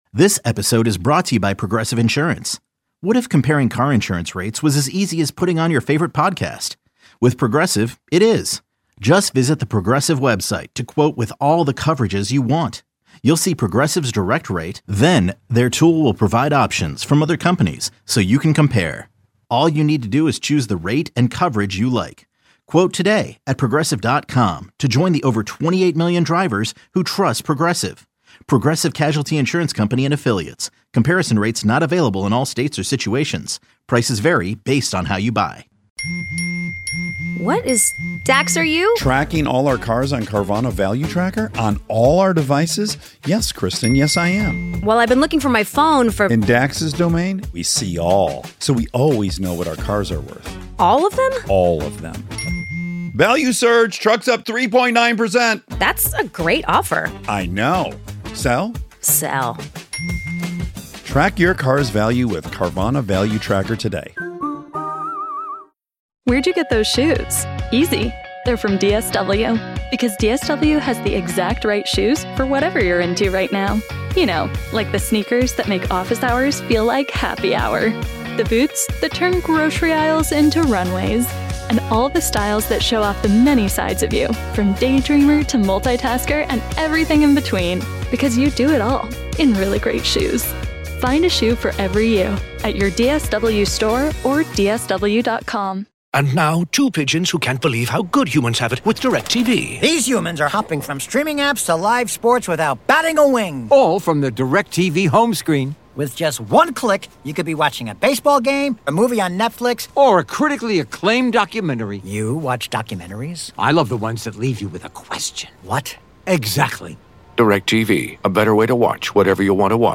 fun, smart and compelling Chicago sports talk with great listener interaction. The show features discussion of the Bears, Blackhawks, Bulls, Cubs and White Sox as well as the biggest sports headlines beyond Chicago.